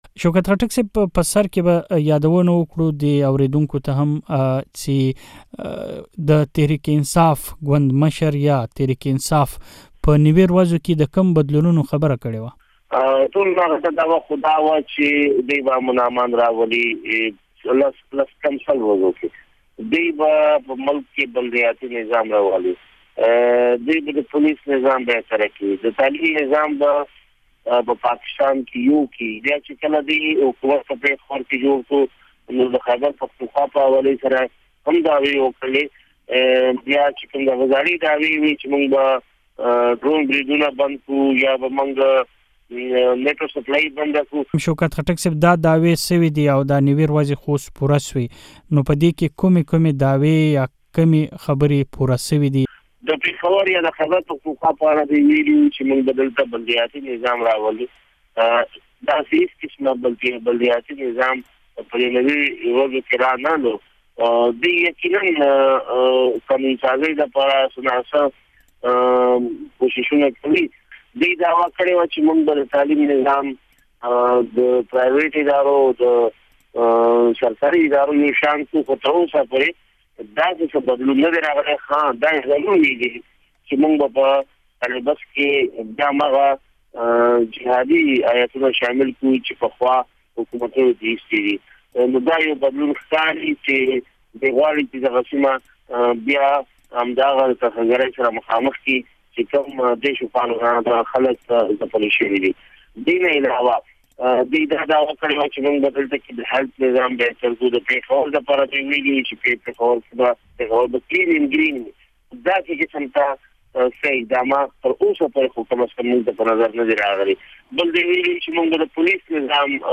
د تحریک انصاف د حکومت ۹۰ ورځې- مرکه